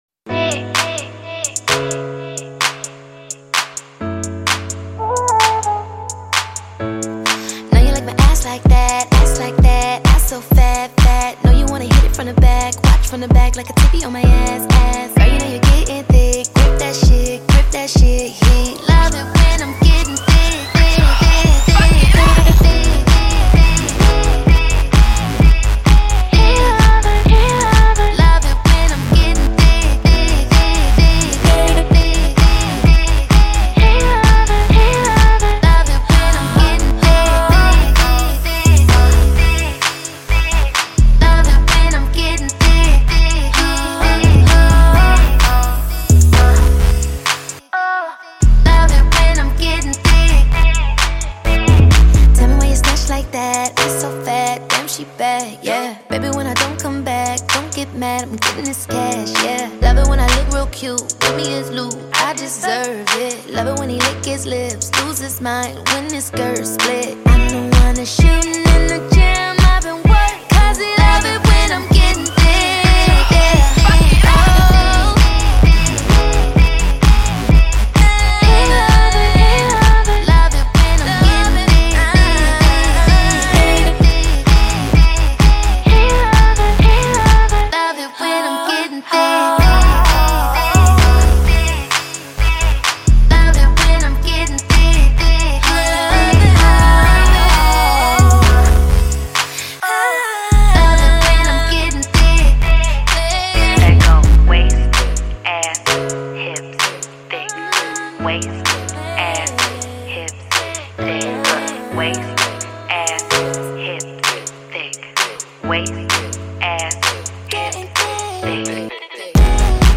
American talented singer and actress